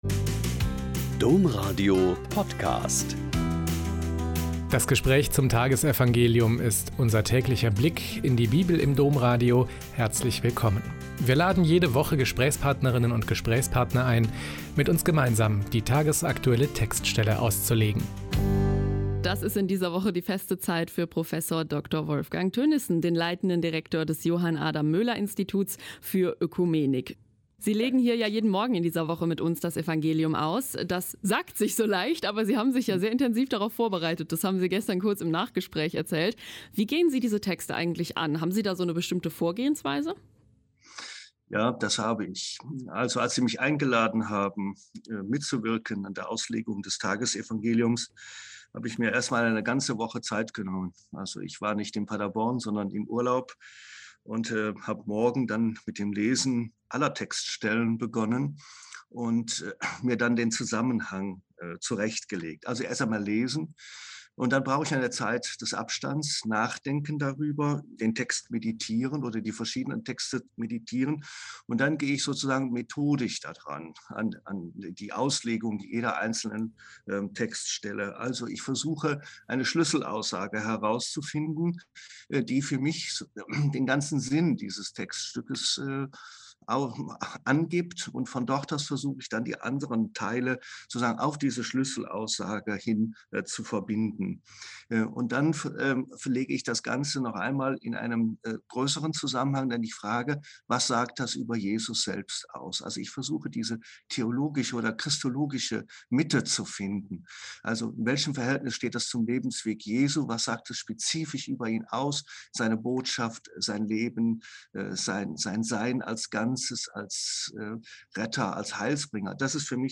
Mt 26,14-25 - Gespräch